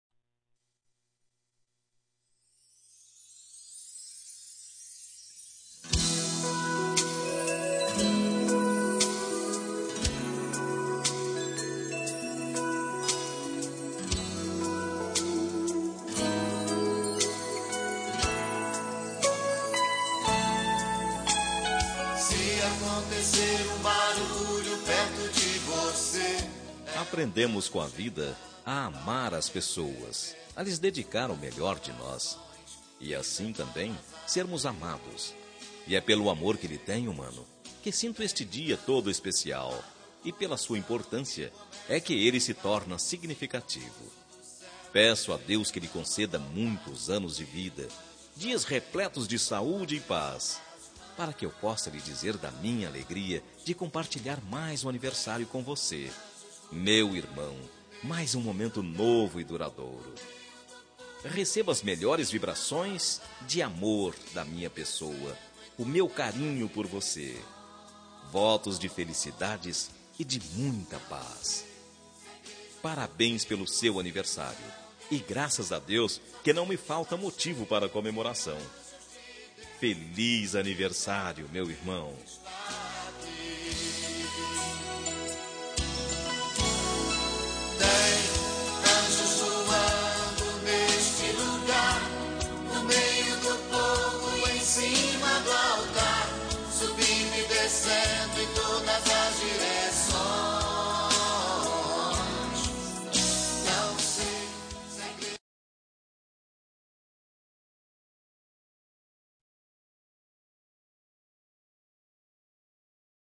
Telemensagem de Aniversário de Irmão – Voz Masculina – Cód: 1742